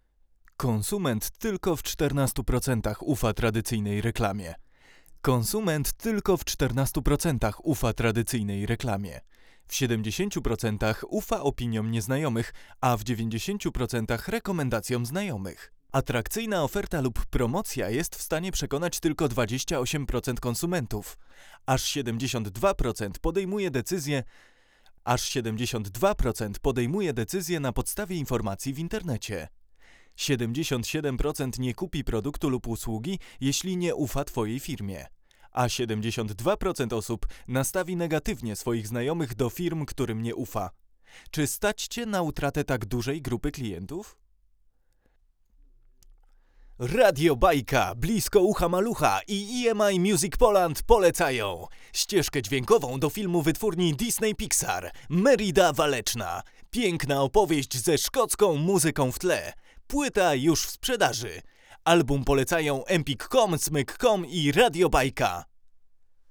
Mocno osadzony, przejrzysty, ale jednocześnie nie wyostrzony dźwięk, którego szuka każdy realizator.